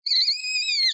PixelPerfectionCE / assets / minecraft / sounds / mob / rabbit / hurt2.ogg